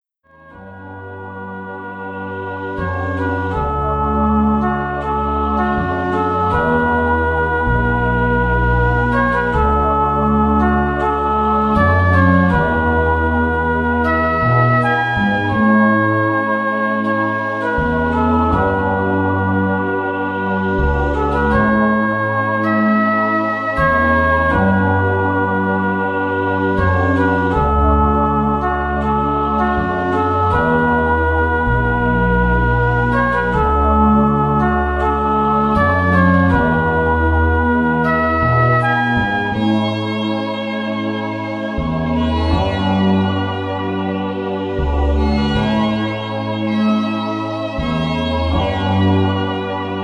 Śpiew ptaków, szum wody, wiejący wiatr czy padający deszcz.